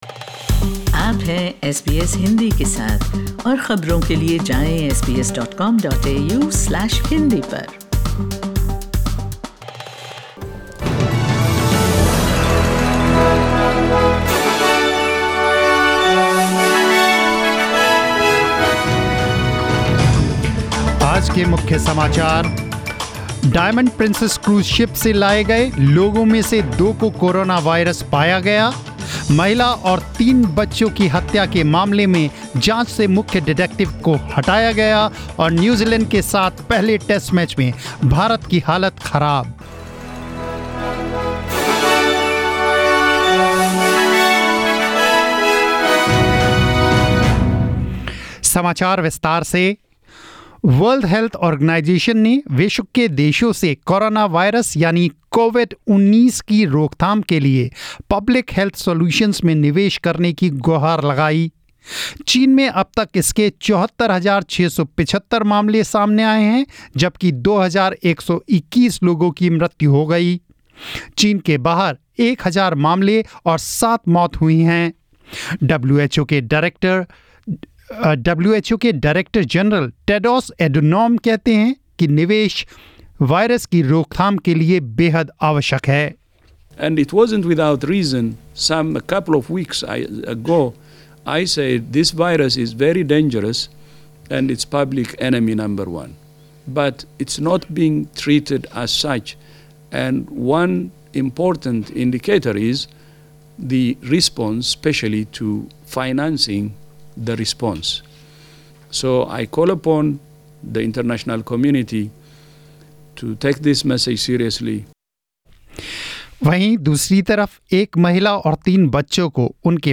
News in Hindi 21 February 2020